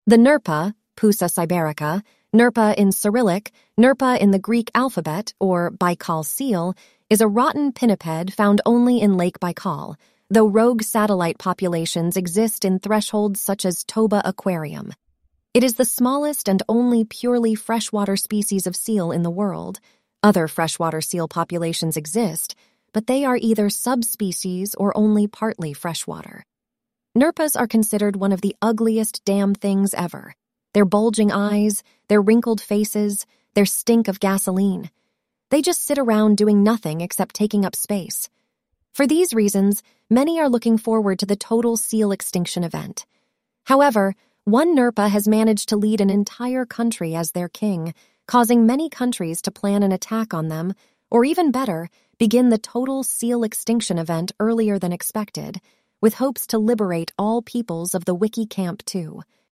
ElevenLabs essentially creates AI voice clips.
ElevenLabs_reads_Nerpa_excerpt.mp3